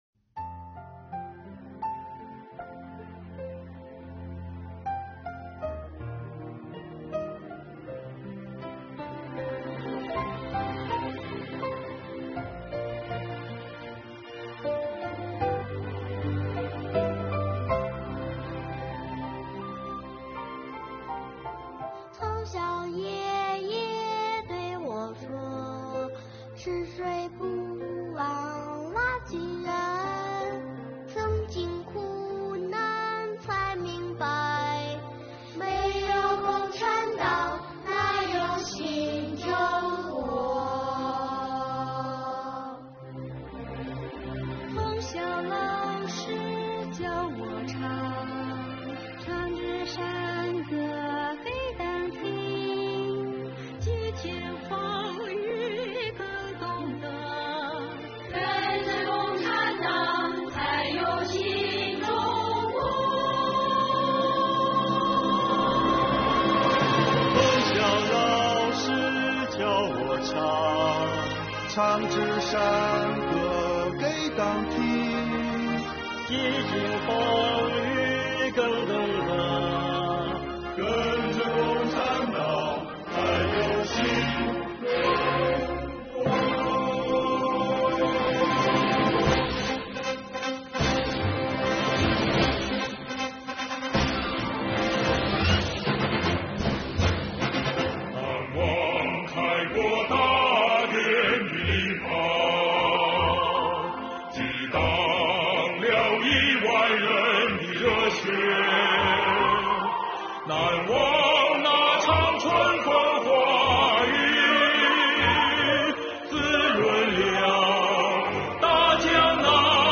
标题: 《在灿烂的阳光下》，税务人唱给你听！